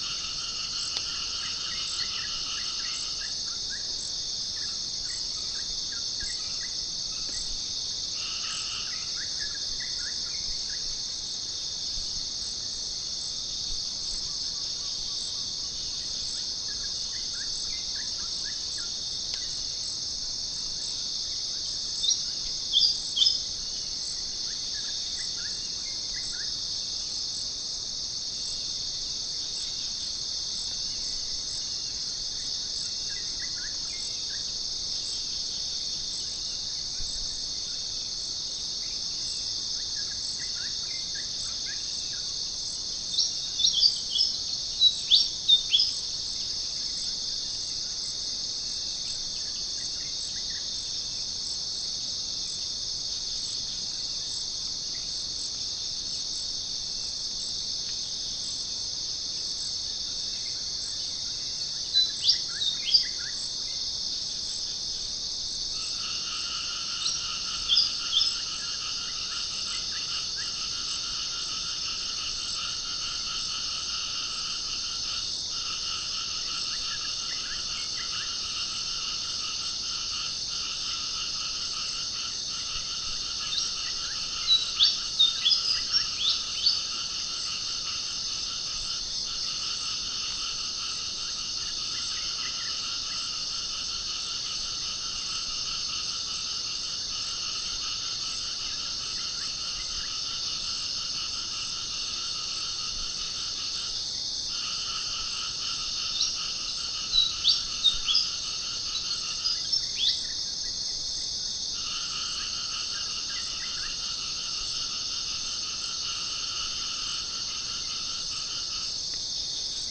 Gallus gallus
Geopelia striata
Pycnonotus goiavier
Pycnonotus aurigaster
Orthotomus ruficeps
Prinia familiaris